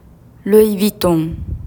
LOUISの語尾のSは発音しない。またVUITTONのONは鼻母音なので、鼻にかけることを意識するとなお良し。